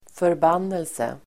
Uttal: [förb'an:else]